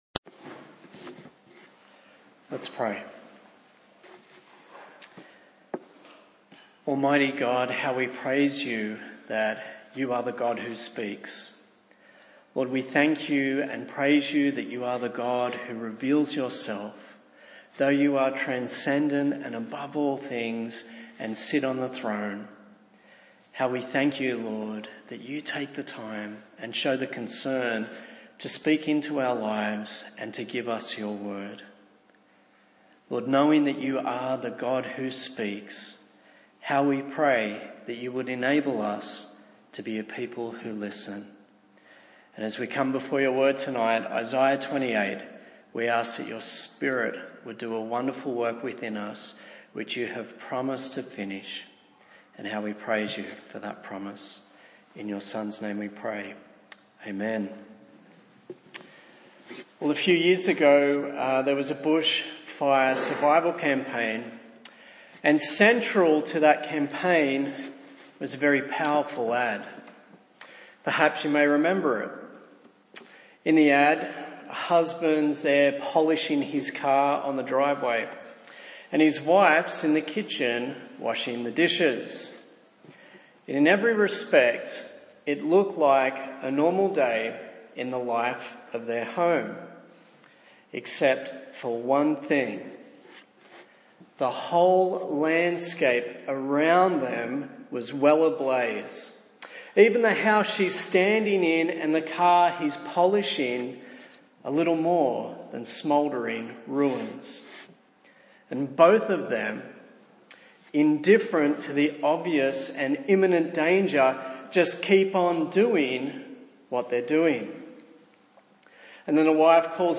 A sermon in the series on the book of Isaiah